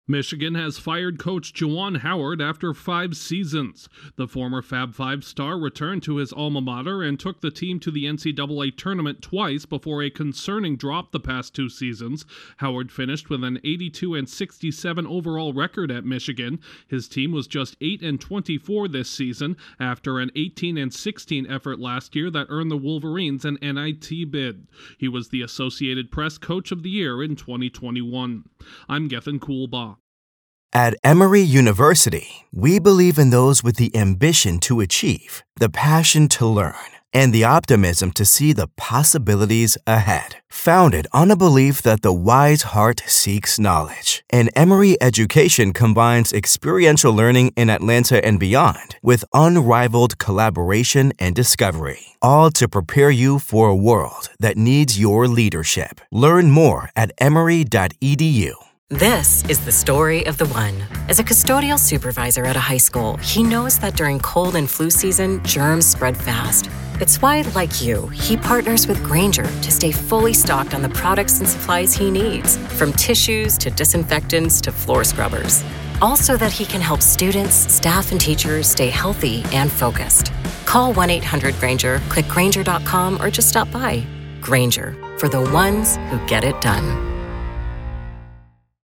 A member of the iconic Fab Five at Michigan has been fired by his alma mater. Correspondent